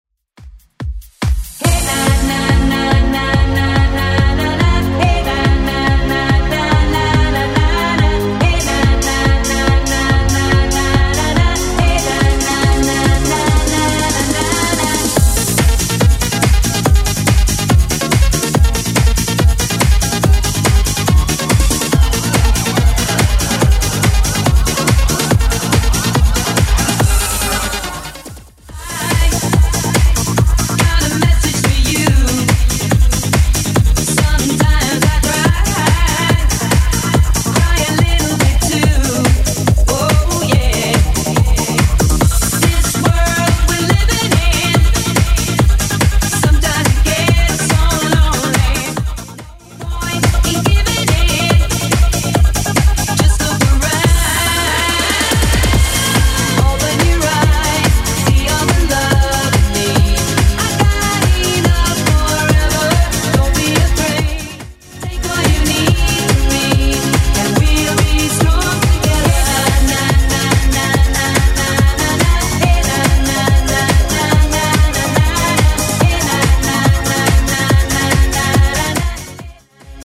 Genre: 80's